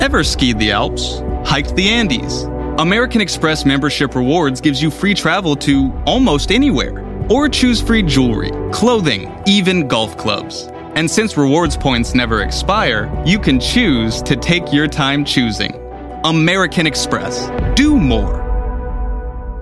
American Express - Commercial - Confident